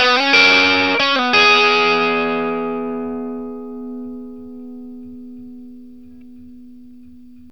BLUESY1 CS60.wav